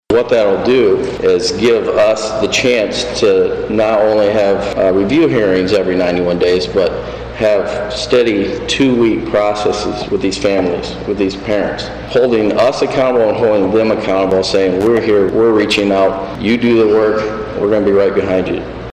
During their meeting Tuesday, the St. Joseph County Commission heard the Juvenile Division’s annual report for 2024.
Kane says they hope to hear soon if the Juvenile Division will be getting some grants they applied for, which will be used to start at Family Treatment Court. Kane explains what that will be.